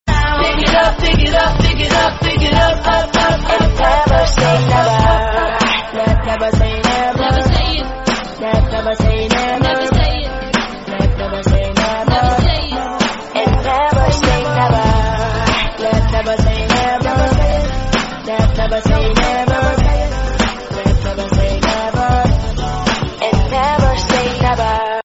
Synthetik